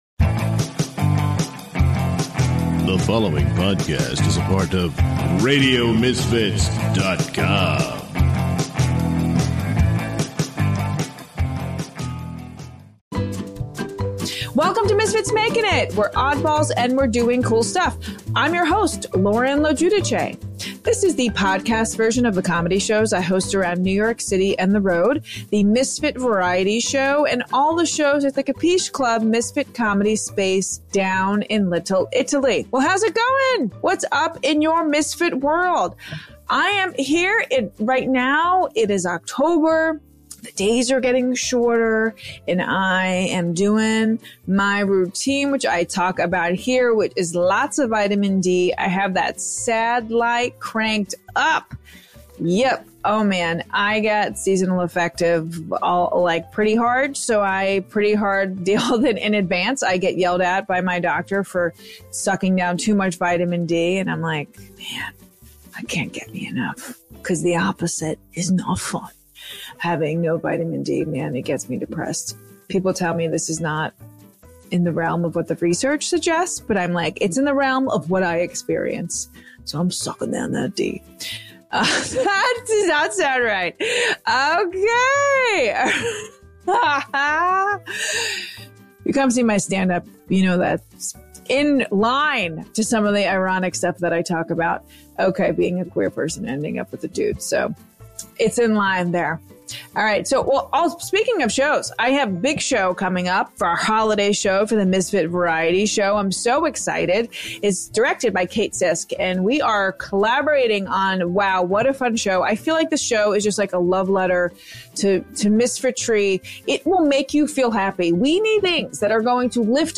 Genres: Comedy , Comedy Interviews , Improv